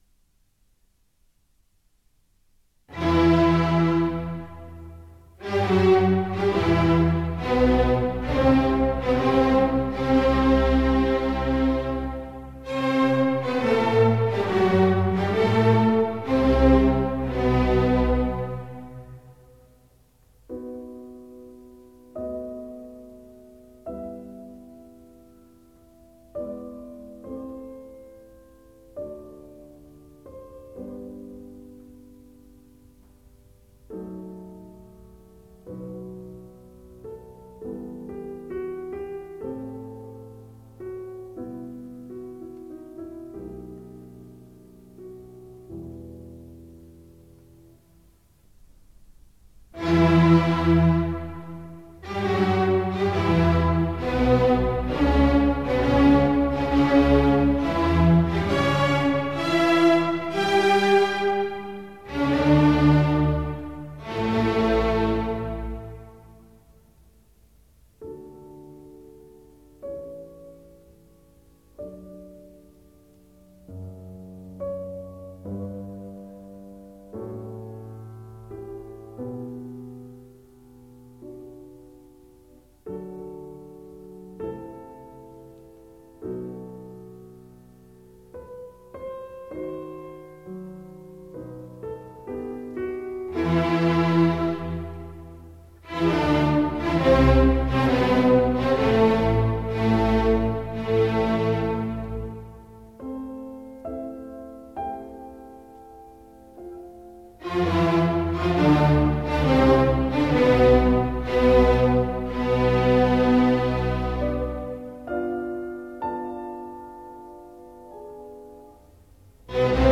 录音地点：柏林耶稣基督大教堂
乐队非常大气，声低异常浑厚。
虽名为《第一号》钢琴协奏曲，却比他的《降 B大调第二号钢琴协奏曲》的完成要晚三年，只是由于前者出版早而被定为第一号。